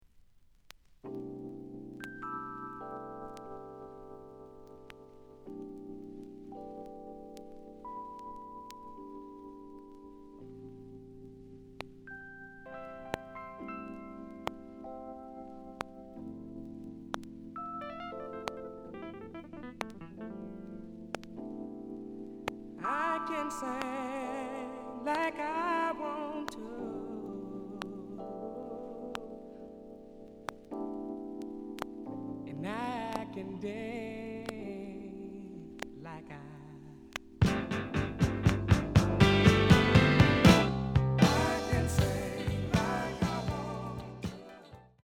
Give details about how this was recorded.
The audio sample is recorded from the actual item. Some click noise on beginning of B side due to scratches.